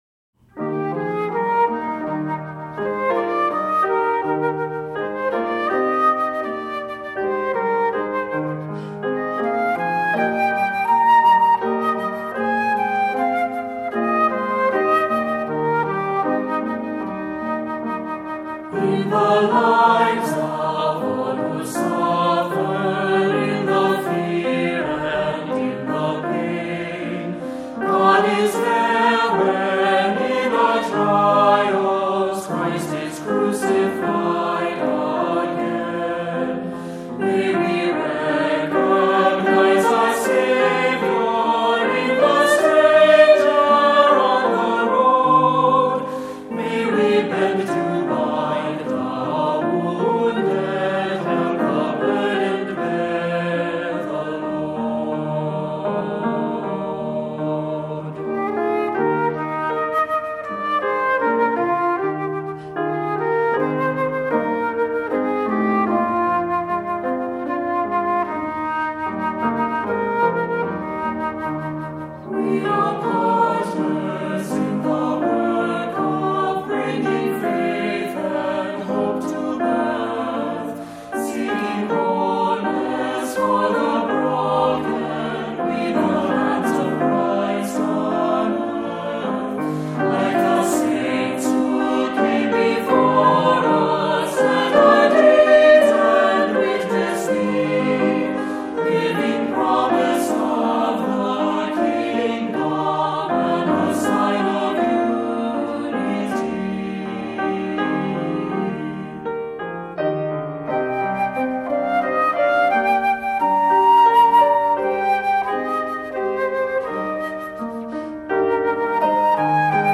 Voicing: SAB; Descant; Assembly